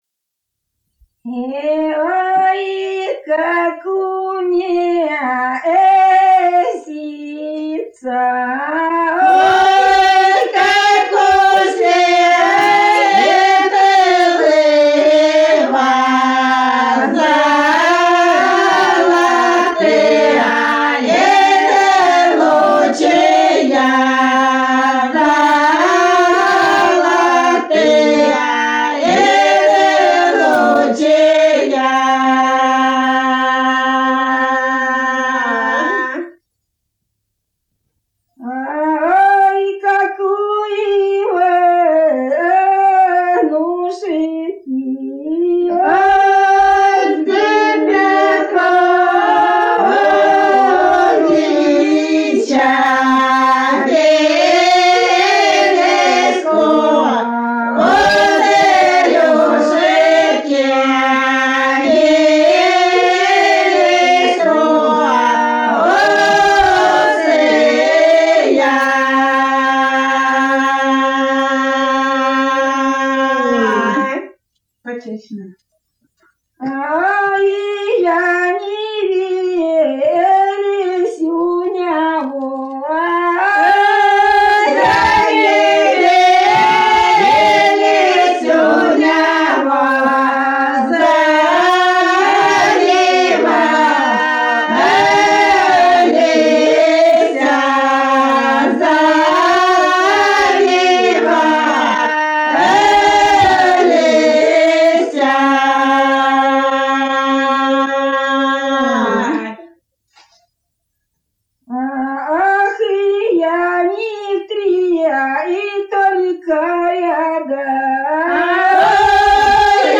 Народные песни Касимовского района Рязанской области «Ой, как у месяца», свадебная.